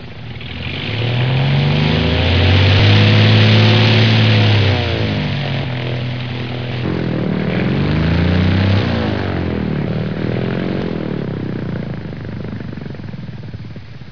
دانلود آهنگ طیاره 32 از افکت صوتی حمل و نقل
جلوه های صوتی
دانلود صدای طیاره 32 از ساعد نیوز با لینک مستقیم و کیفیت بالا